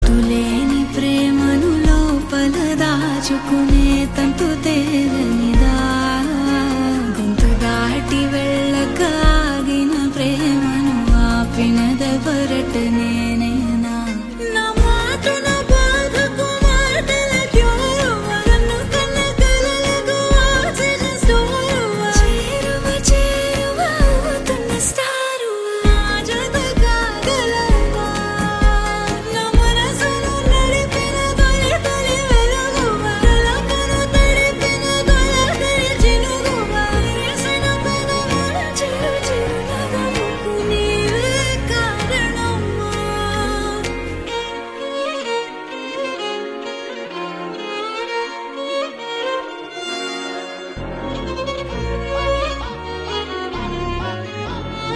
high-energy swag Romantic melody Chill Groovy BGM Dance